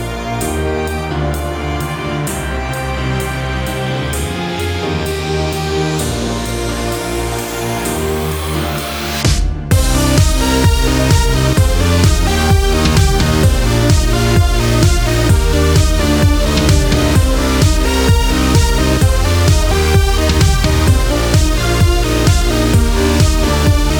no Backing Vocals R'n'B / Hip Hop 4:06 Buy £1.50